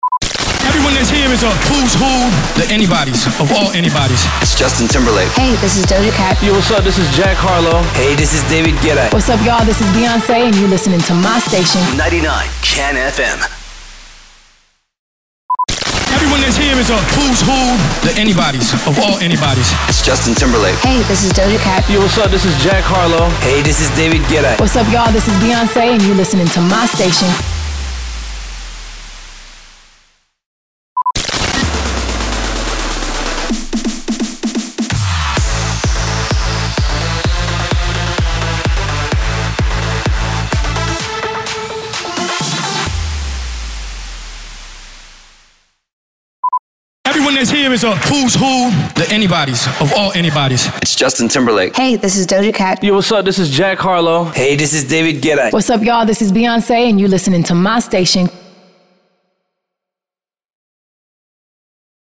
594 – SWEEPER – ARTIST MONTAGE